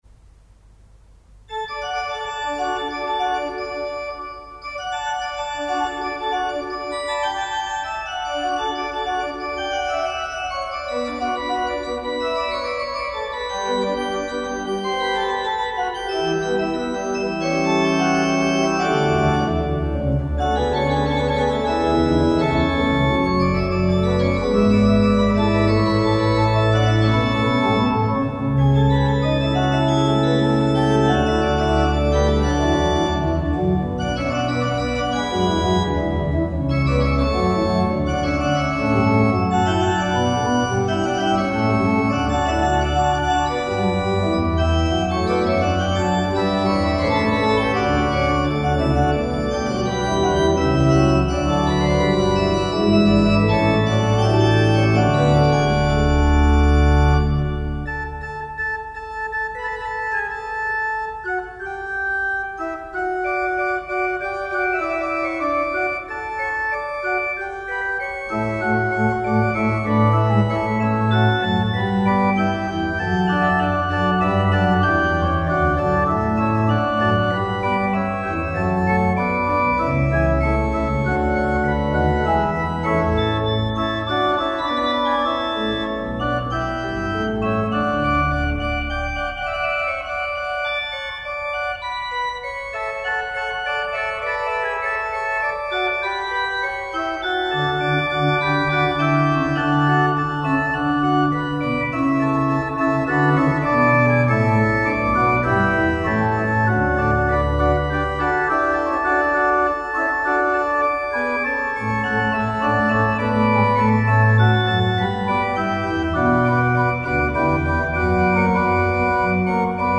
an der Orgel der Petri-Kirche